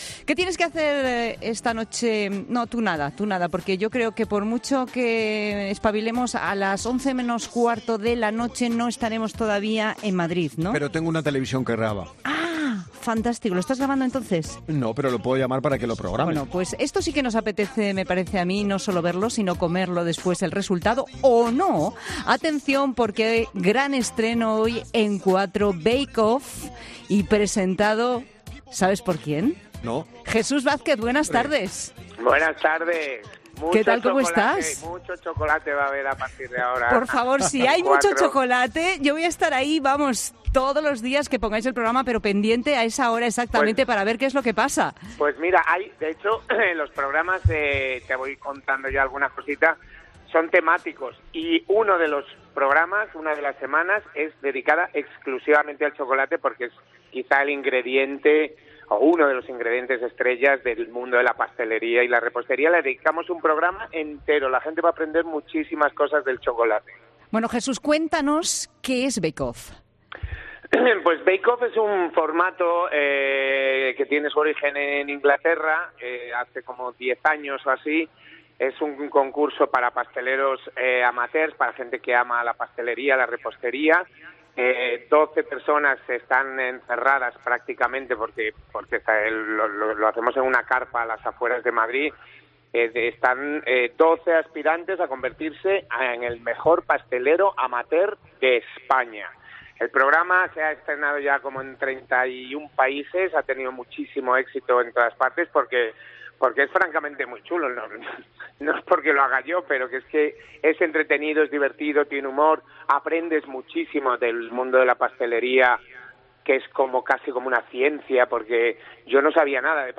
A pocas horas del estreno, el presentador ha pasado por los micrófonos de 'La Tarde' de COPE para desvelarnos algunas de las sorpresas que nos esperan en "Bake off".